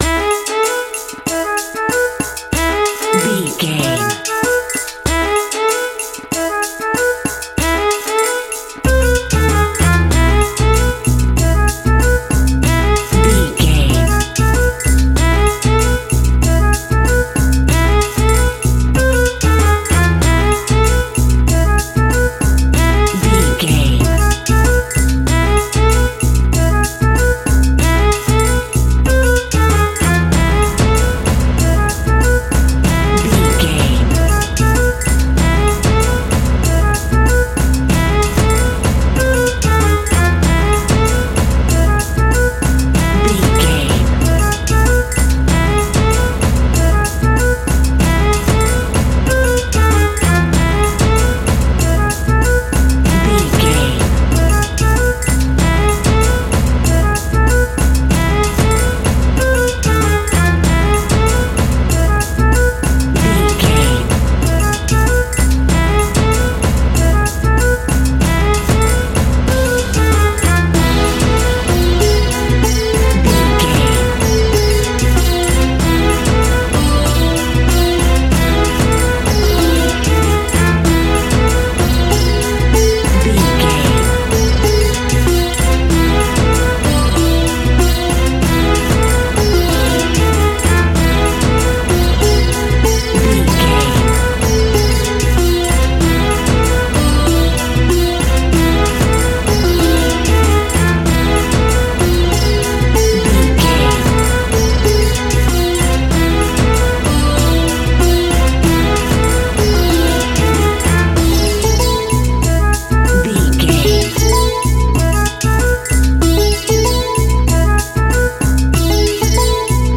Ionian/Major
instrumentals
sitar
bongos
sarod
tambura